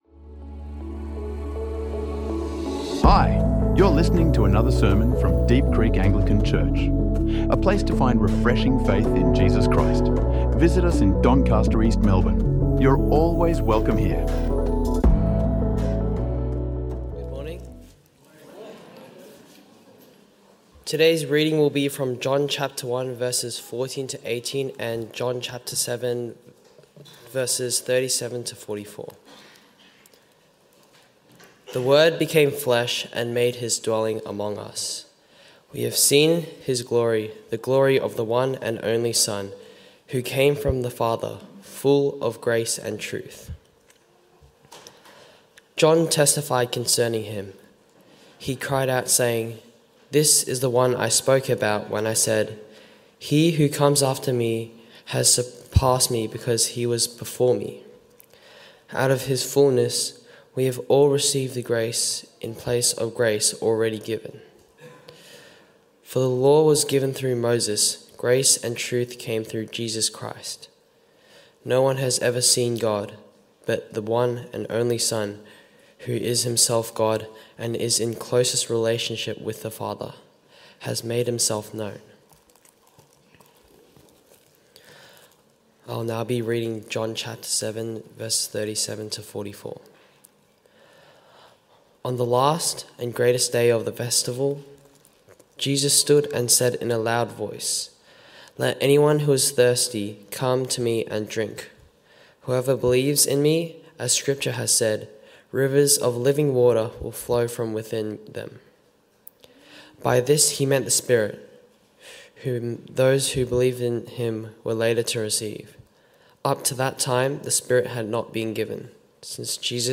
The sermon from Deep Creek Anglican Church explores the significance of Jesus's birth, emphasizing the importance of understanding not just the "how" but the "why" behind the Christmas story. It explains that the glory of God is brought close to humanity through Jesus, who embodies grace and truth, allowing people to access God's glory and experience spiritual transformation.